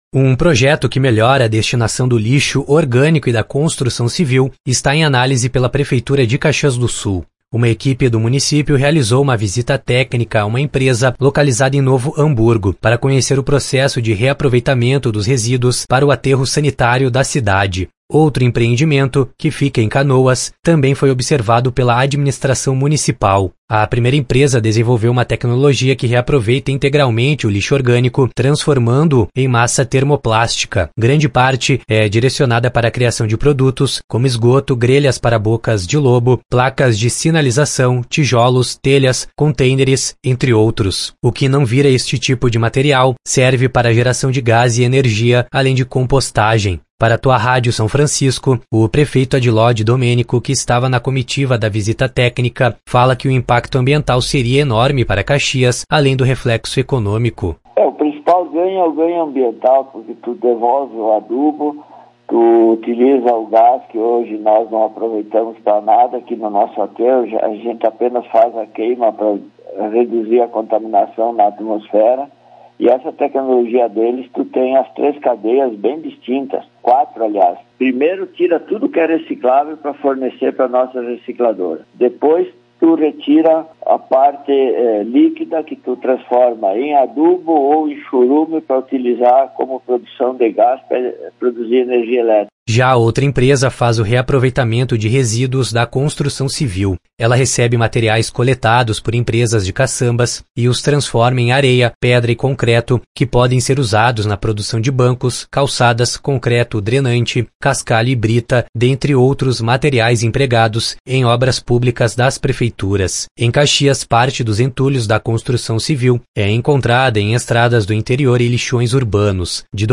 Para Tua Rádio São Francisco, o prefeito Adiló Didomenico, que estava na comitiva da visita técnica, fala que o impacto ambiental seria enorme para Caxias, além do reflexo econômico.
Didomenico ressalta a experiência em visitar a companhia.